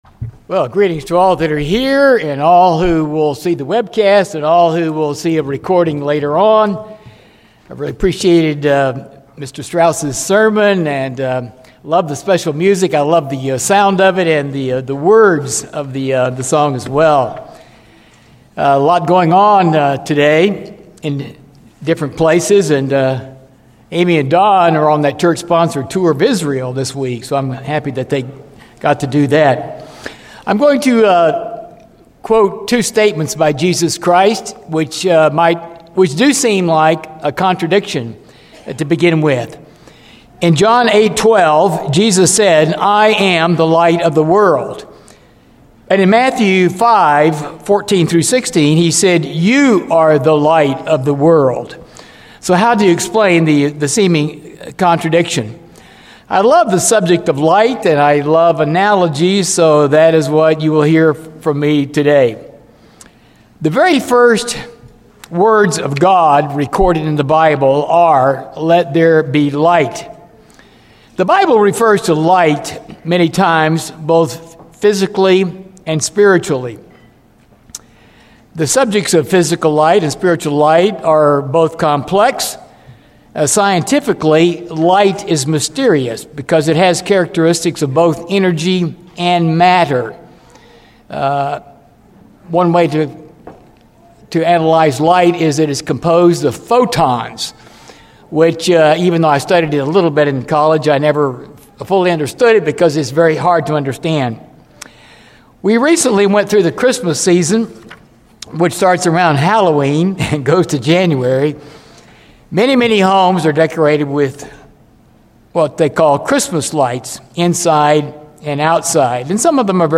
Given in Dallas, TX